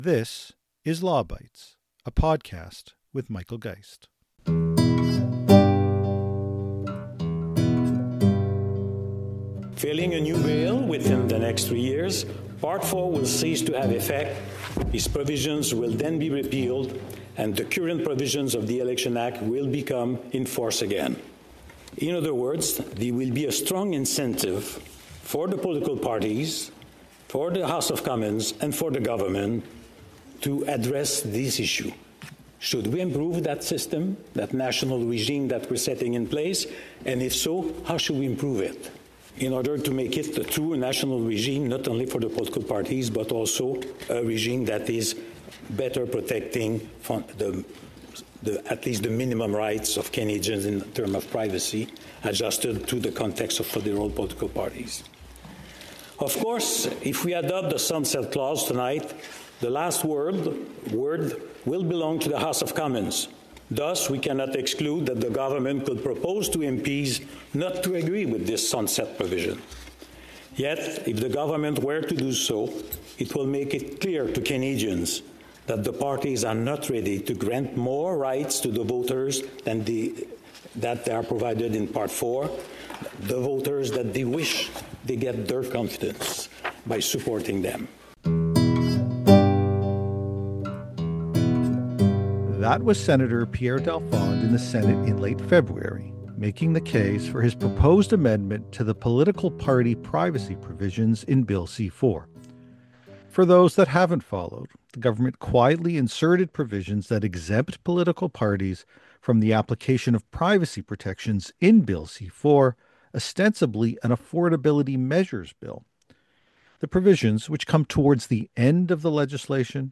There were witnesses from advocacy groups, but the episode focuses on testimony from privacy commissioners (current and former) along with Elections Canada leadership.